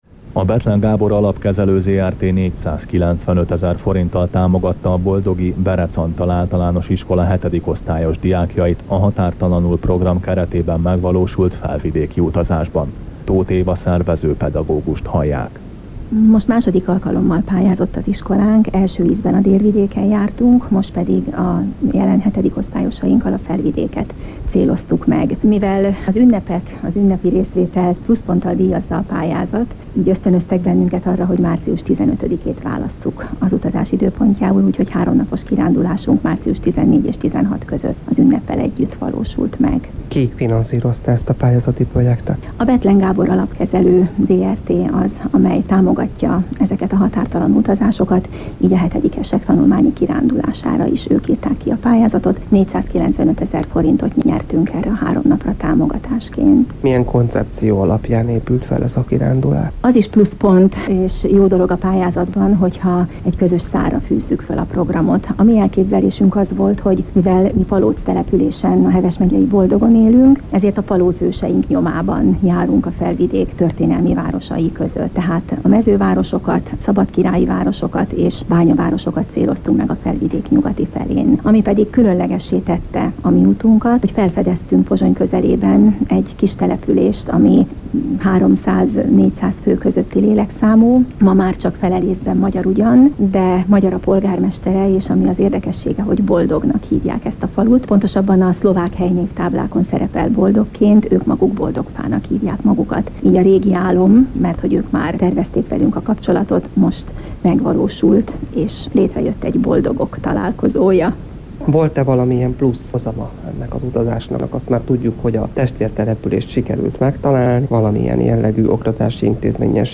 radioriport_fel.mp3